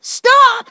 synthetic-wakewords
synthetic-wakewords / stop /ovos-tts-plugin-deepponies_Spike_en.wav
ovos-tts-plugin-deepponies_Spike_en.wav